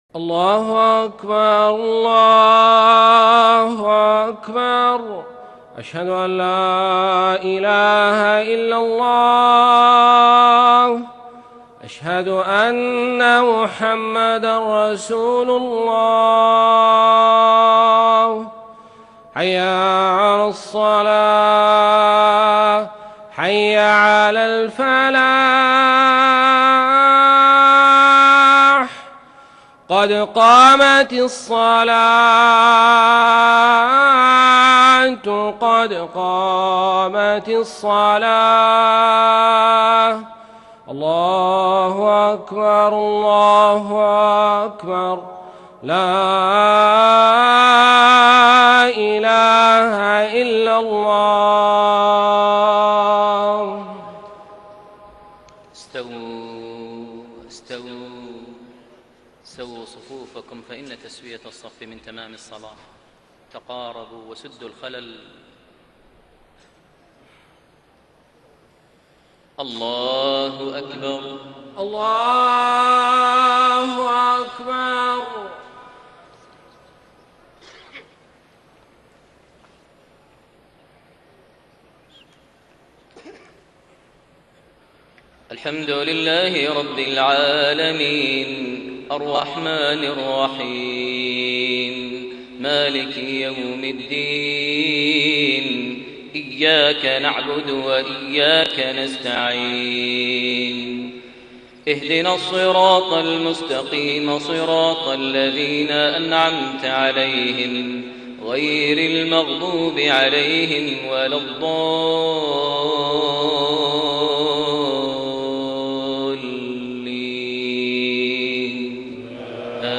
صلاة المغرب 23 ذو القعدة 1432هـ | سورة القيامة > 1432 هـ > الفروض - تلاوات ماهر المعيقلي